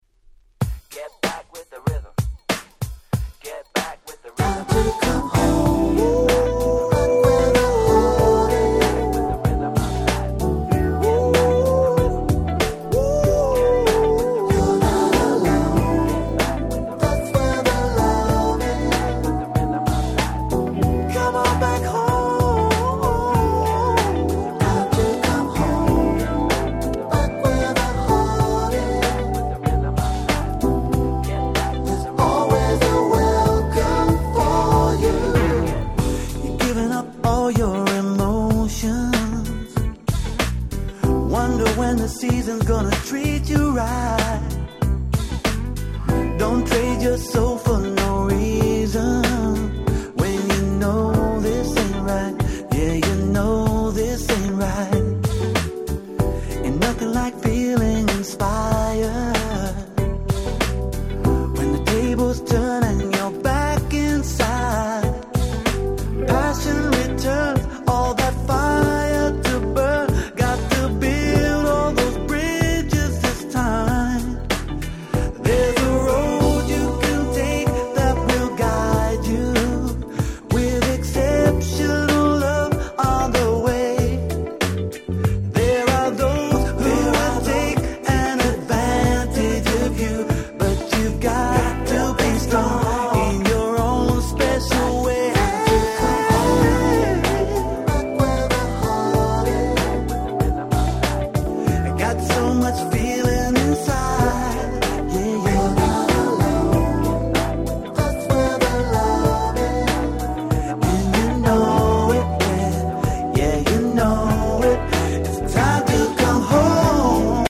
99' Nice UK Soul LP !!
切ないMelodyが堪らないめちゃくちゃ良い曲です！